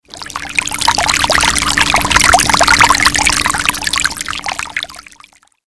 Главная » Рингтоны на звонок телефона » Звуки » Вода